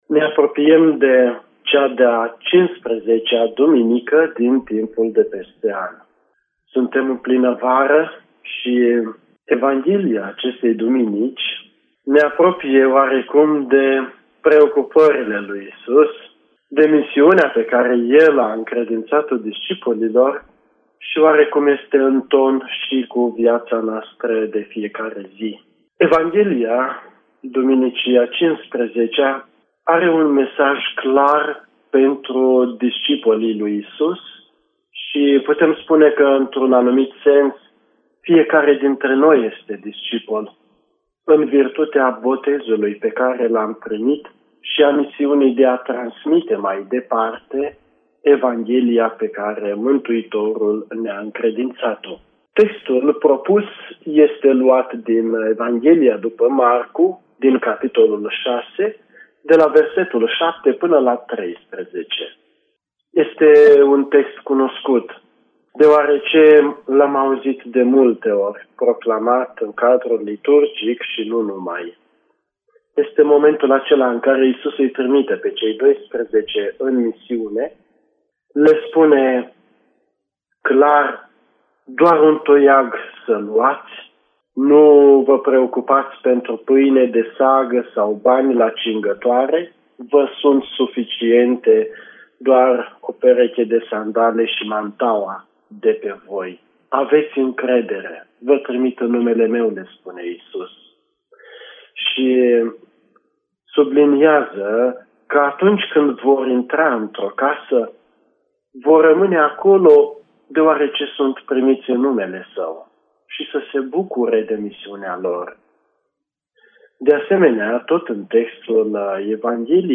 Reflecția la Evanghelia duminicii